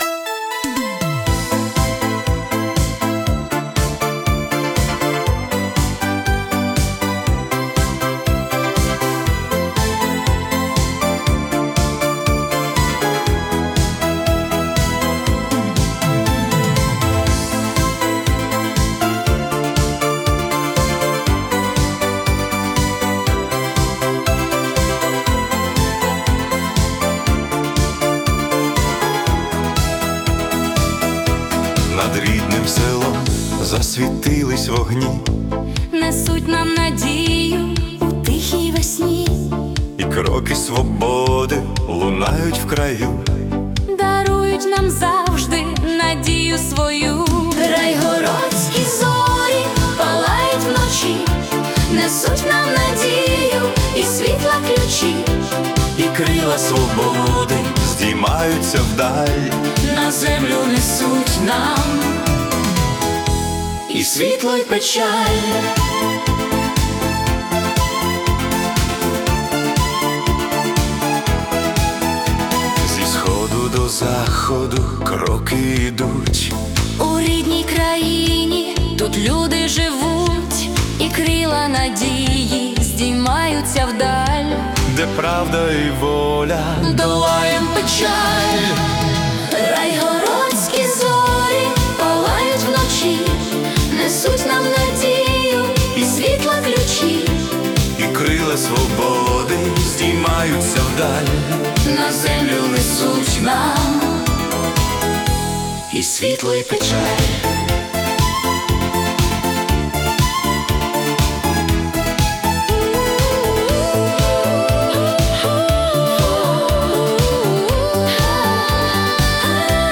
🎵 Жанр: Italo Disco / Synth-Pop